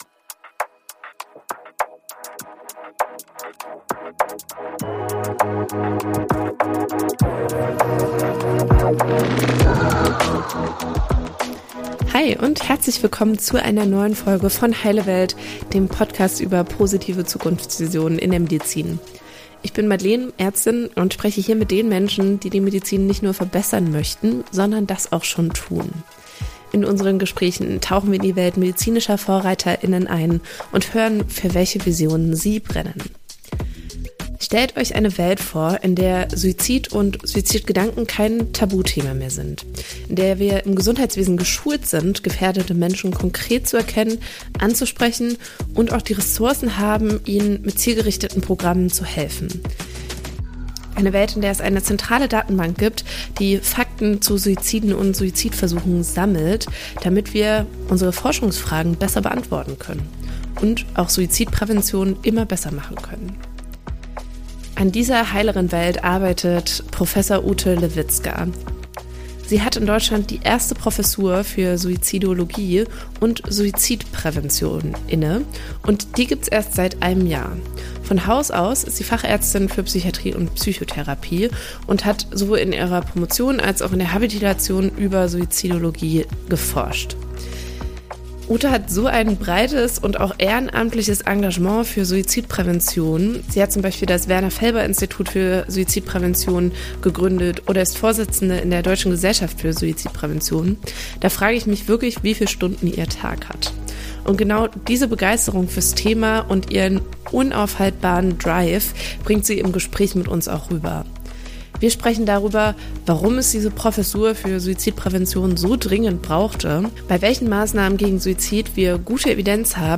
Heilewelt ist der Podcast über Zukunftsvisionen in der Medizin. Hier sprechen die zwei Ärztinnen